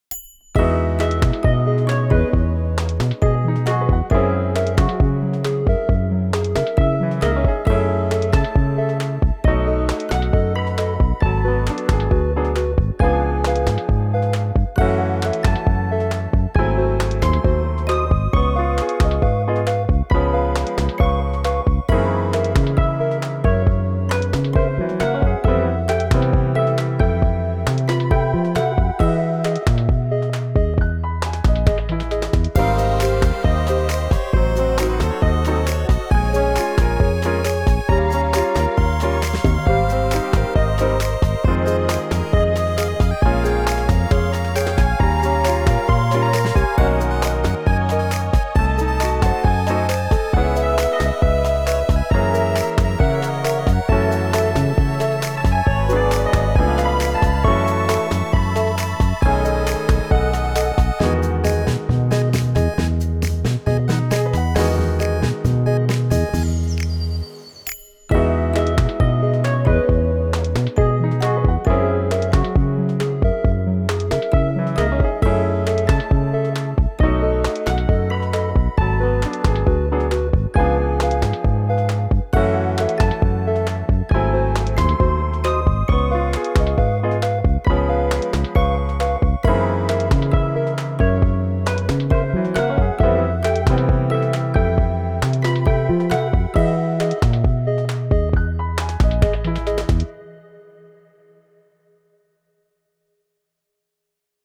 おしゃれ かわいい しっとり FREE BGM